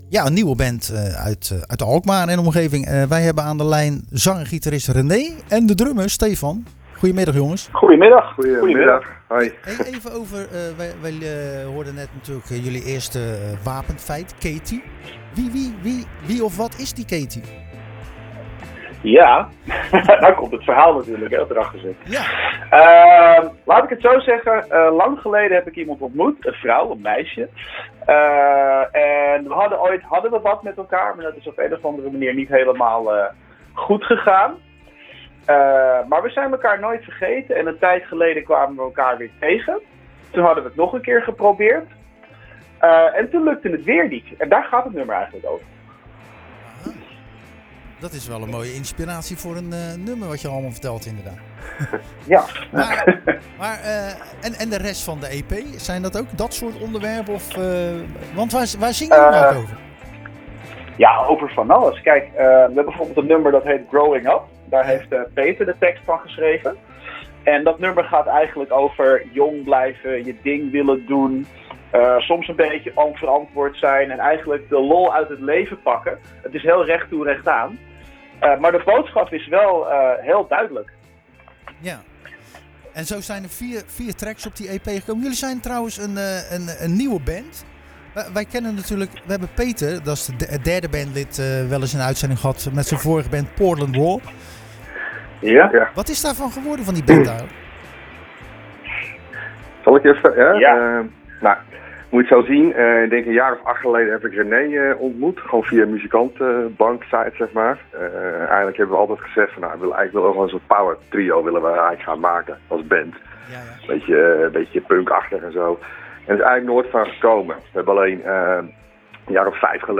De Alkmaarse rockband Stranger In Paradise lieten we tijdens Zwaardvis aan het woord over hun gelijknamige debuut-EP.